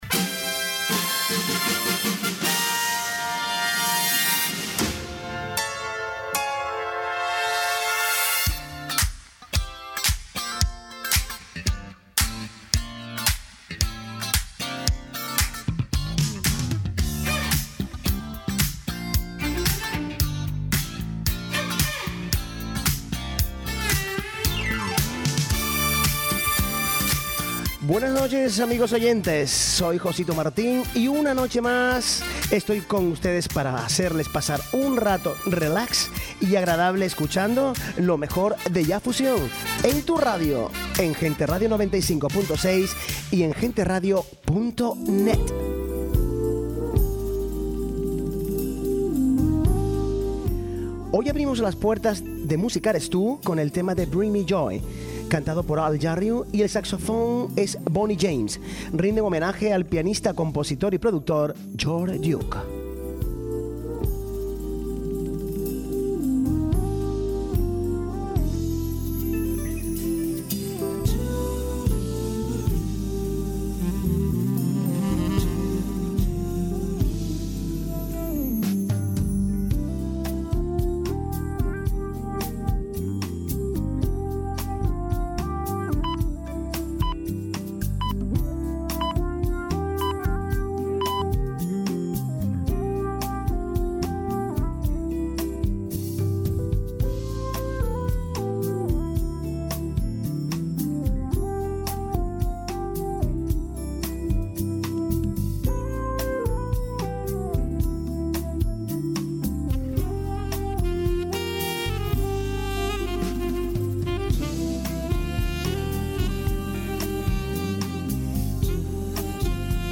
Música eres tú - Jazz Fusion - Gente Radio